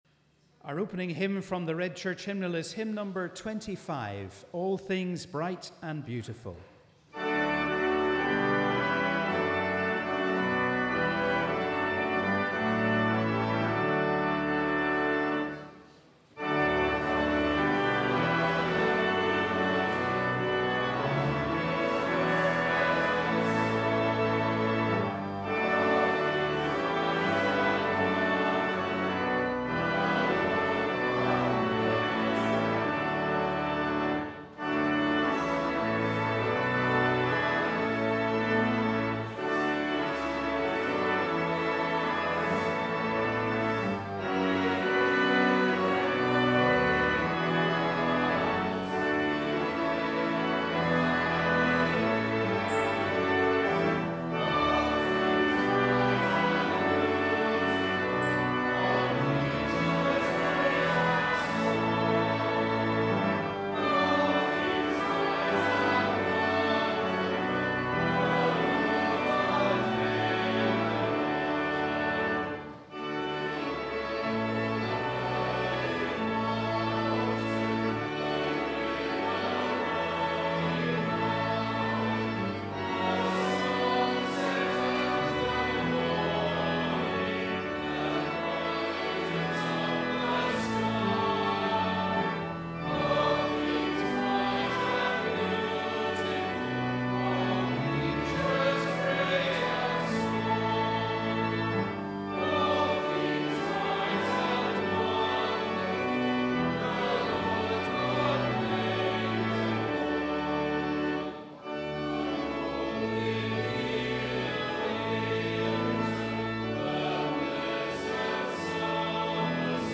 Wherever you are, we warmly welcome you to our service of Morning Prayer on the 1st Sunday after Trinity.